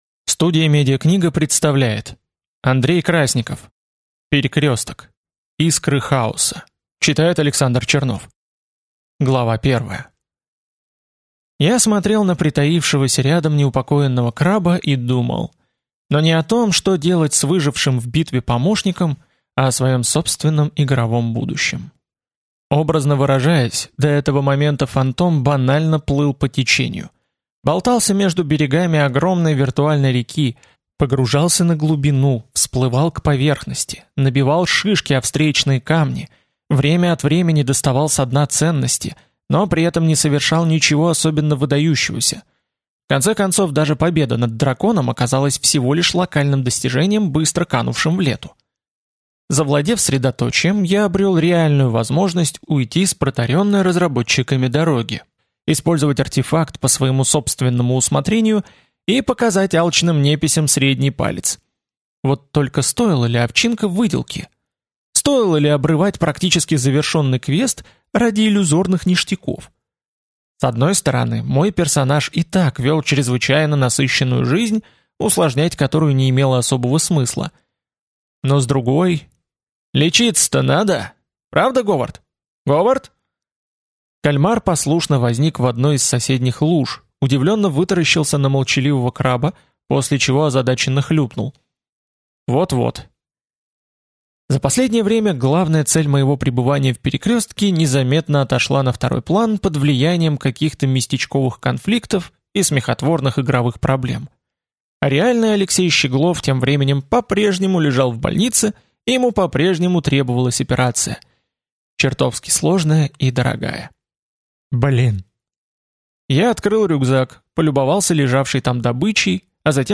Аудиокнига Искры хаоса | Библиотека аудиокниг